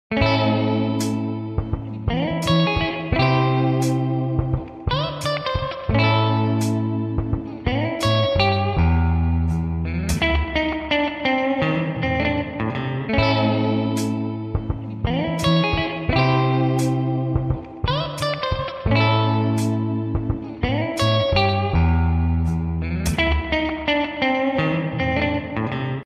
Theme Song Ringtone